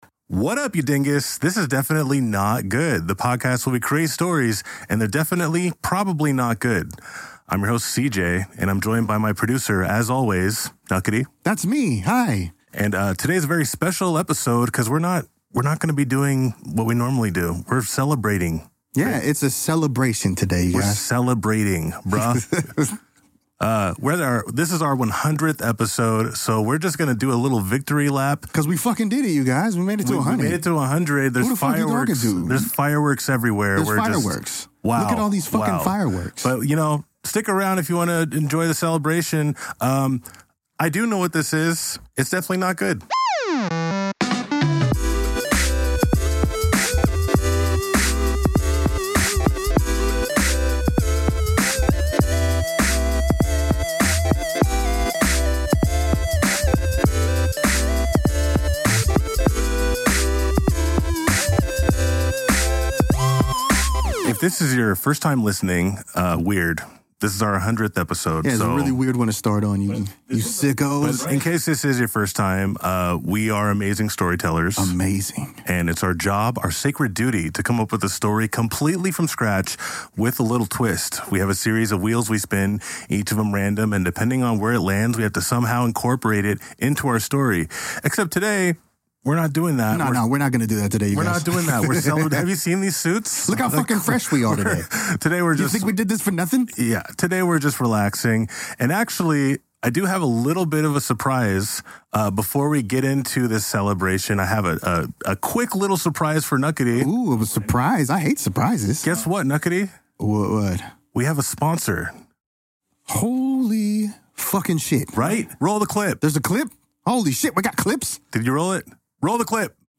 Genres: Comedy , Comedy Fiction , Fiction , Improv